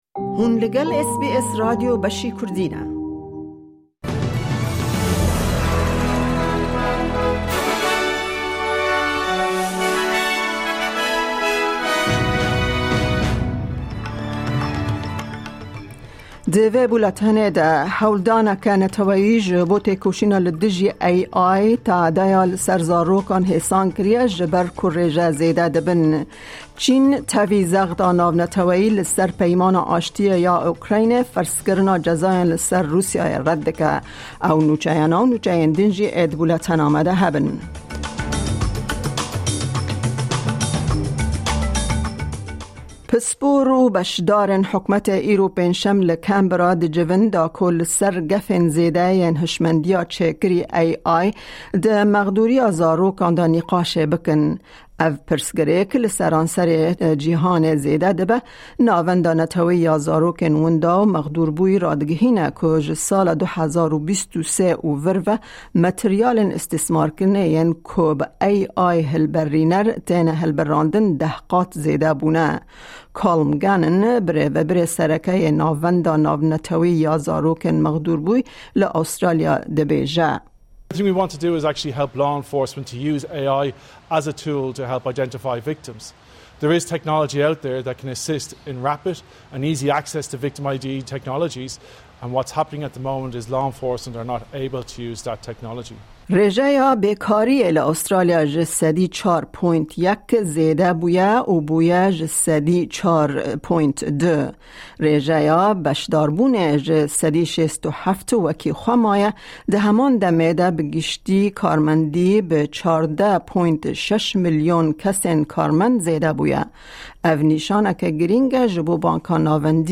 Nûçeyên roja Pêncşemê, 17î Tîrmeha 2025